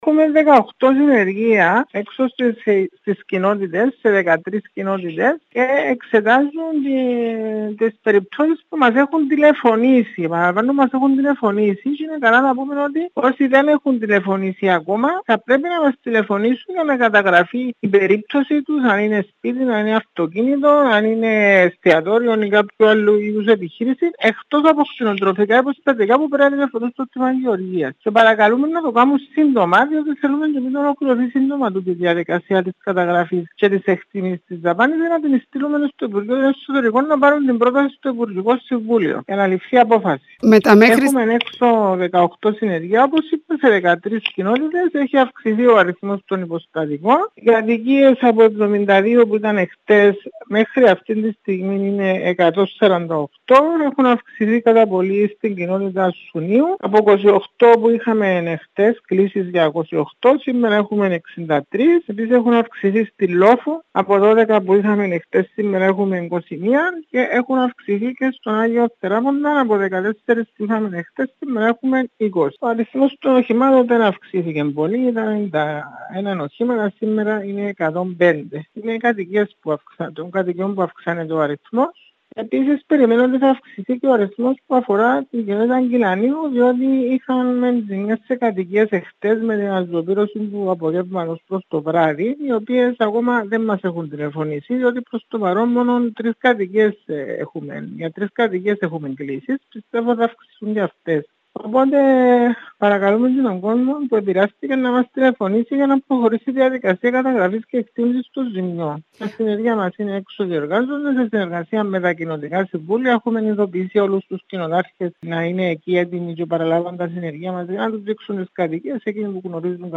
Μιλώντας στο ΚΑΝΑΛΙ 6 η Έπαρχος Λεμεσού Ηλέκτρα Παναγιώτου ανέφερε ότι αυξάνονται συνεχώς οι αναφορές για ζημιές σε υποστατικά και οχήματα.